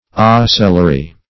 Ocellary \O*cel"la*ry\